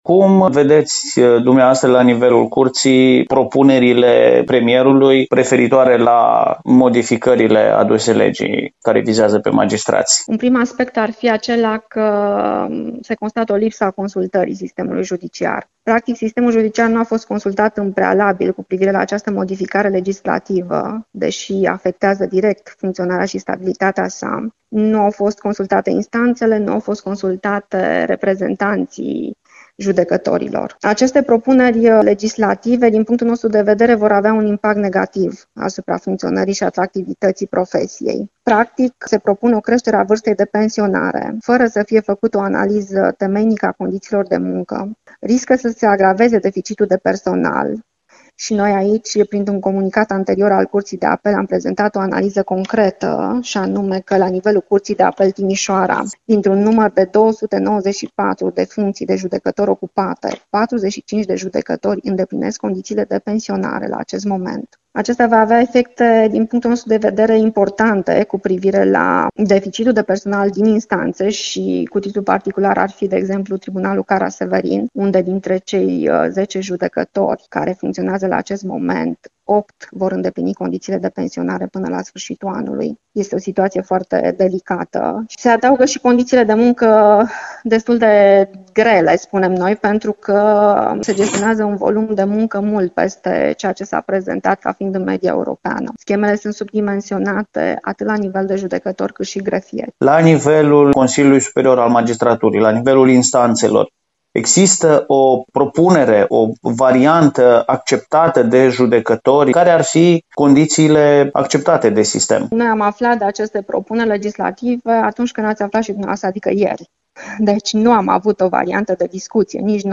INTERVIU| Judecătorii, nemulțumiți de lipsa de consultare privind modificarea pensiilor
Într-un interviu pentru Radio Timișoara, președintele Curții de Apel Timișoara, judecătoarea Maria Dica, a declarat că dacă propunerile premierului vor deveni realitate, mulți judecători care îndeplinesc condițiile de pensionare vor ieși din sistem, cea mai gravă situație fiind la Tribunalul Caraș-Severin.